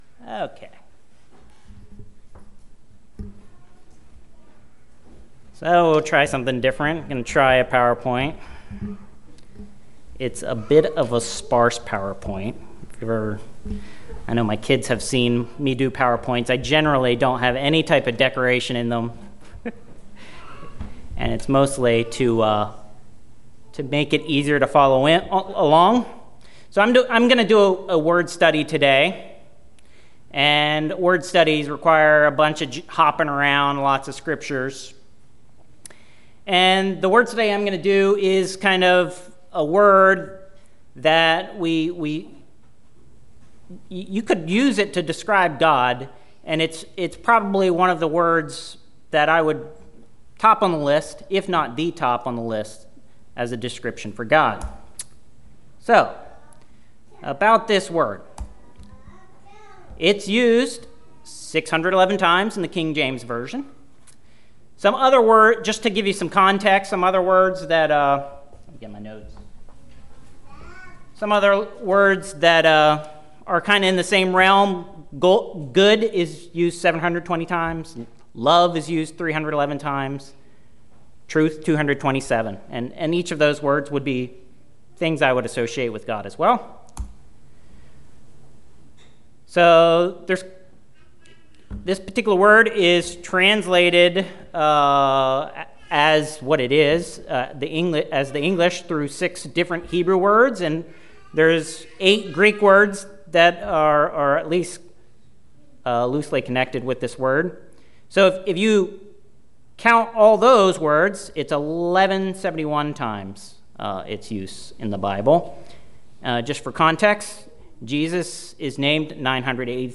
Word study sermon that addresses the significance of the word "holy", what is holy, and who has the power to make things holy.